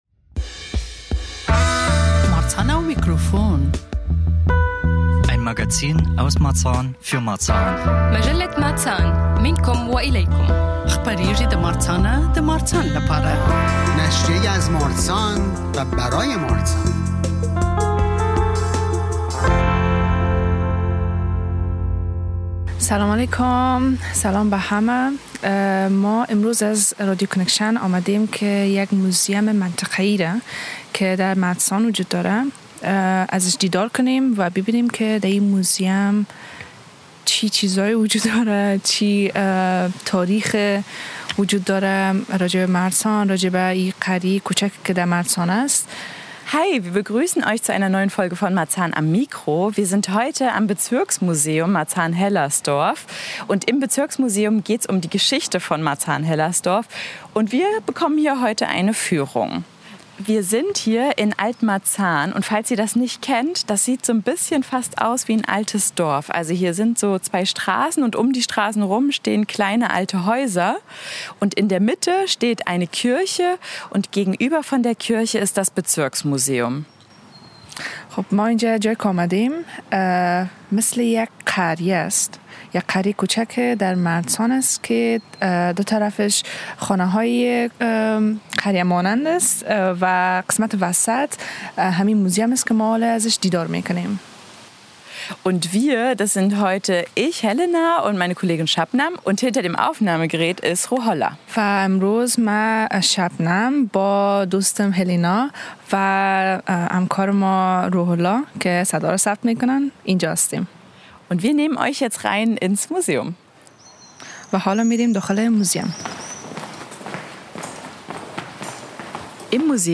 In dieser Folge von Marzahn am Mikro nehmen wir Euch mit ins Bezirksmuseum Marzahn-Hellersdorf.